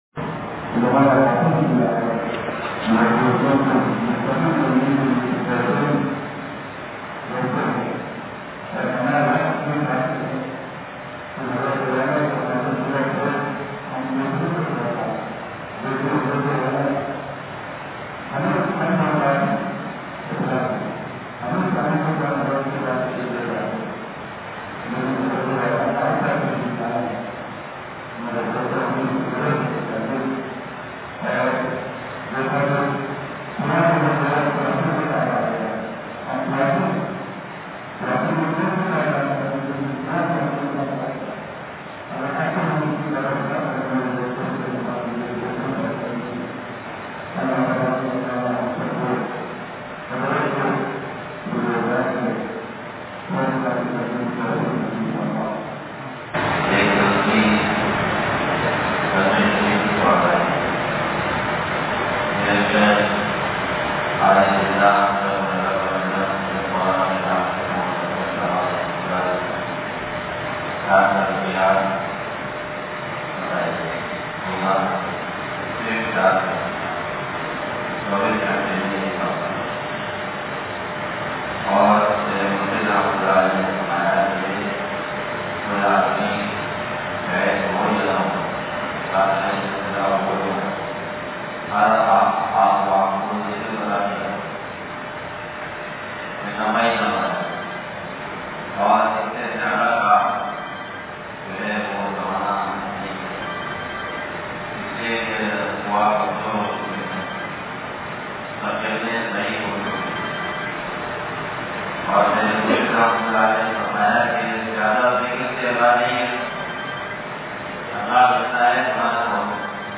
بیان – انصاریہ مسجد لانڈھی